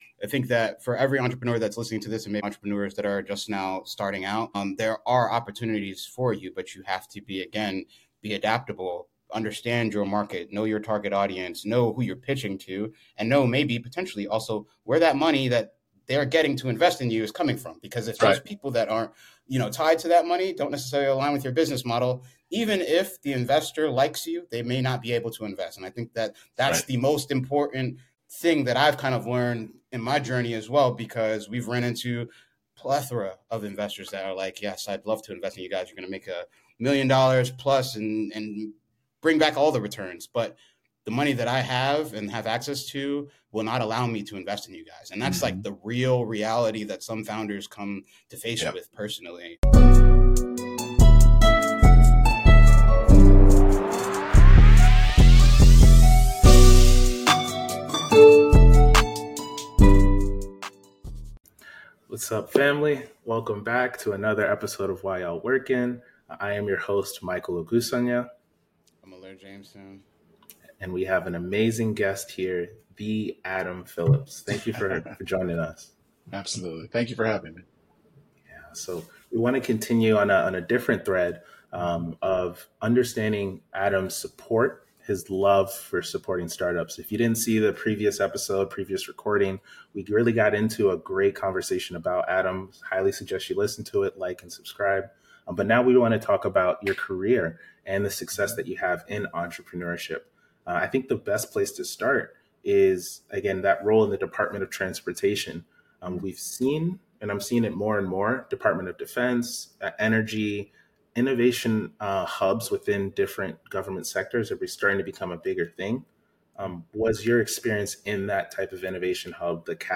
join us on an epic part 2 of our interview.